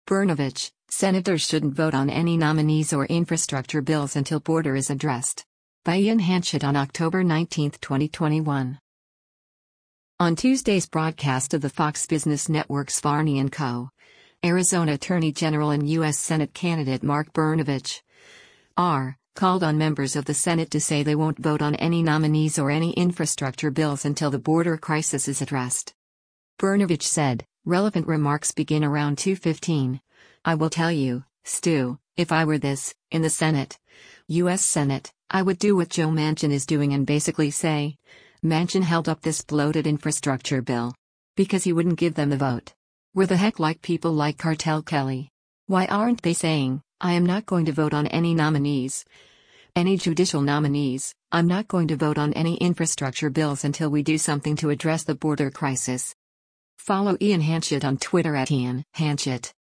On Tuesday’s broadcast of the Fox Business Network’s “Varney & Co.,” Arizona Attorney General and U.S. Senate candidate Mark Brnovich (R) called on members of the Senate to say they won’t vote on any nominees or “any infrastructure bills” until the border crisis is addressed.